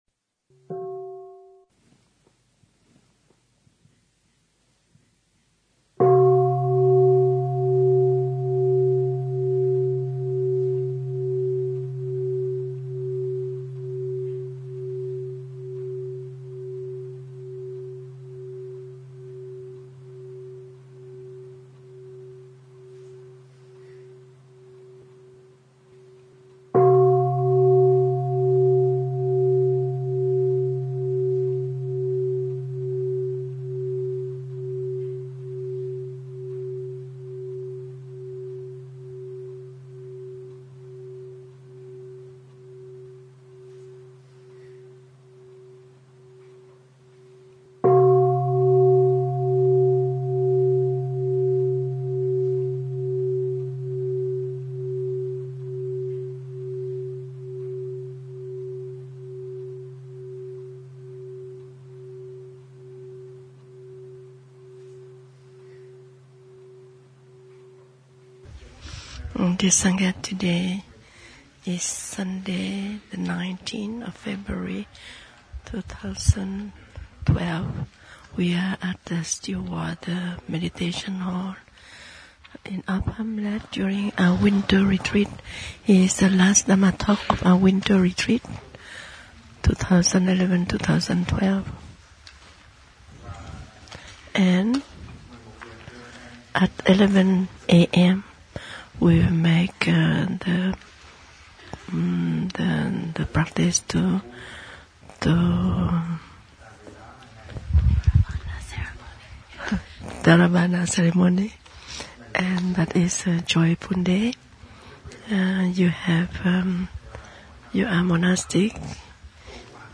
February 19, 2012. 58-minute dharma talk from Upper Hamlet in Plum Village, France. The sangha is in the 2011-2012 Winter Retreat. The talk is given in Vietnamese with English translation.